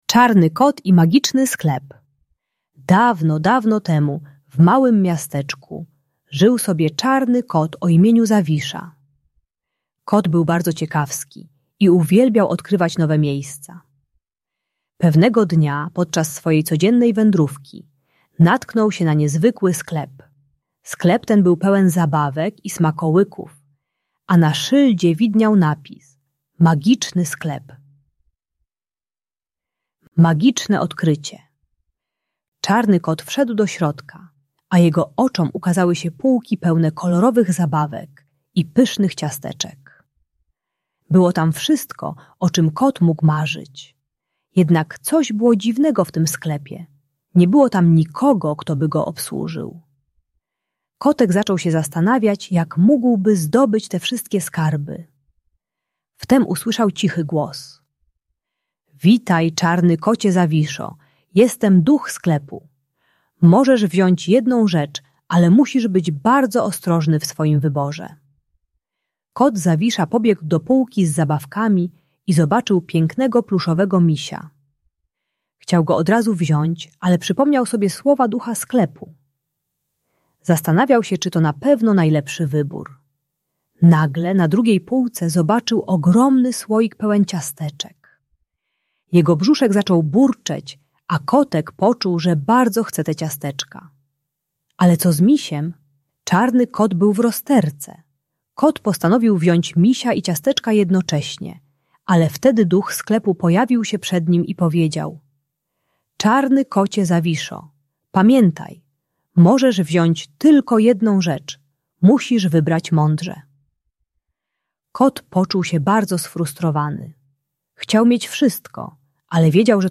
Czarny Kot i Magiczny Sklep - Agresja do rodziców | Audiobajka